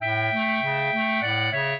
clarinet
minuet10-3.wav